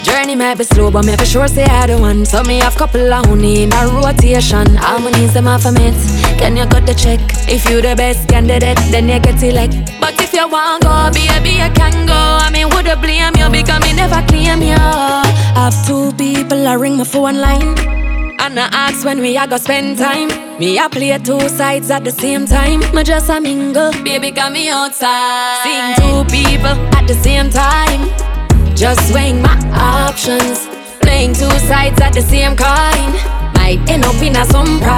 Жанр: Танцевальные / Реггетон
# Modern Dancehall